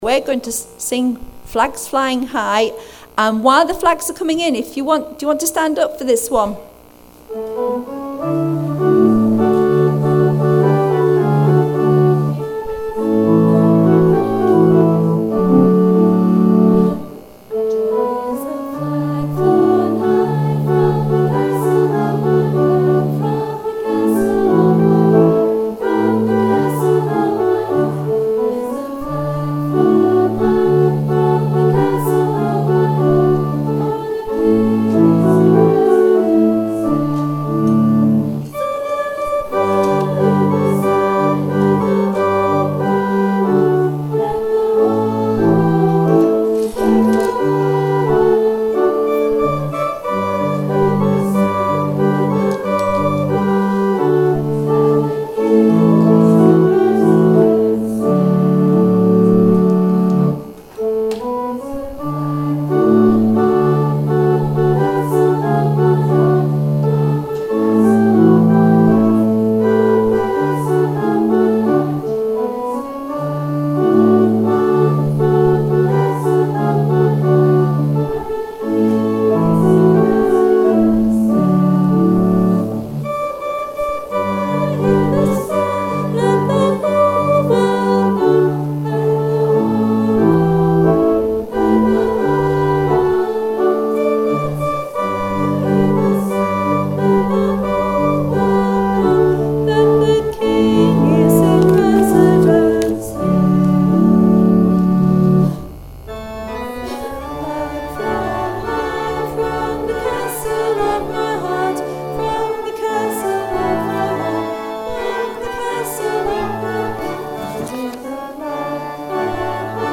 Thinking Day - 19 February 2019
To start the celebration we sang '